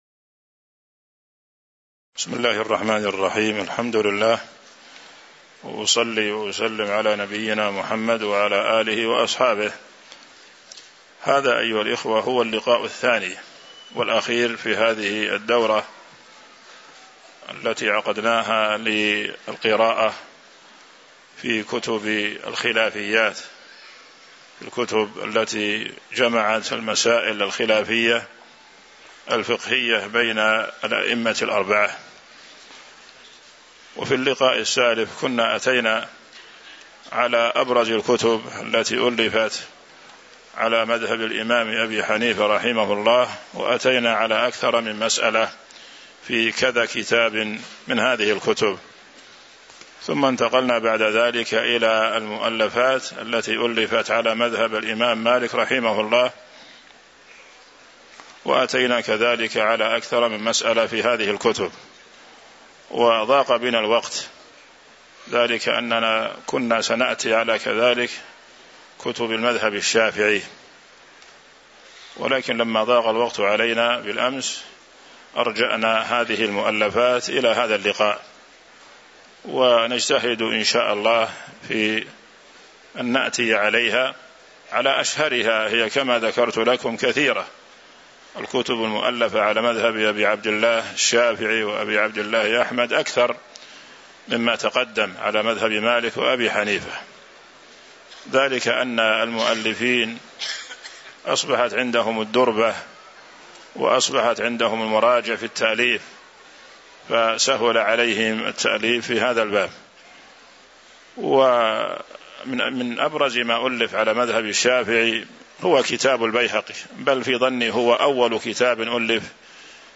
تاريخ النشر ١١ ربيع الأول ١٤٤٦ هـ المكان: المسجد النبوي الشيخ